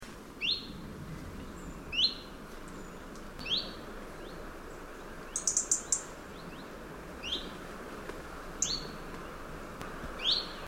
Chiffchaff calls
All from Northwestern Estonia, 10-11 September 2005.
Call 1 10.Sep 2005 Haversi, Noarootsi, Läänemaa, Estonia (108 kB)